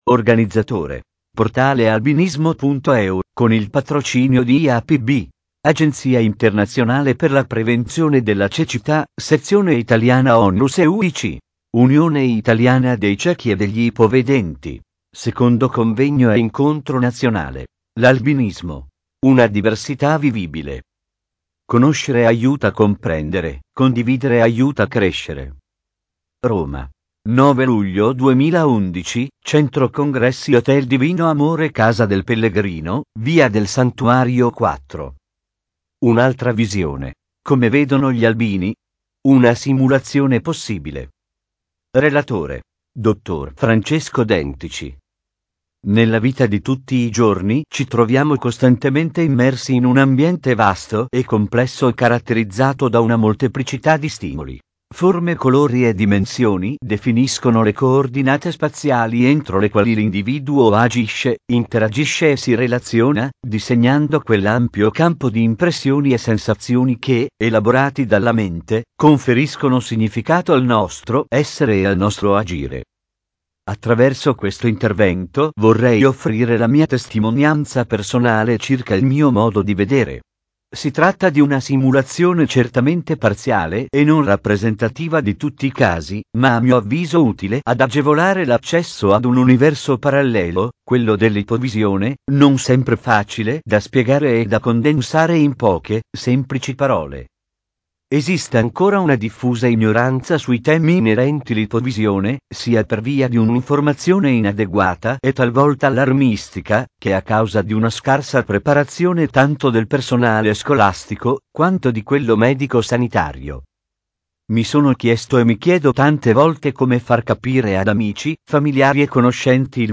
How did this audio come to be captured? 2011 - The Albinism: a livable diversity - 2nd National Conference